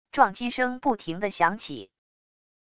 撞击声不停的响起wav音频